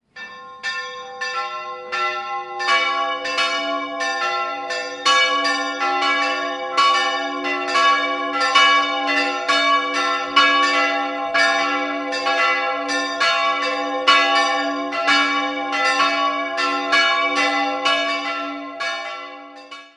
Die katholische Hedwigskirche wurde 1961/62 als fünfeckiger, 25 m hoher Zeltbau errichtet mit einem separaten niedrigen Glockenträger. 3-stimmiges Geläute: cis''-e''-a'' Nähere Daten liegen nicht vor.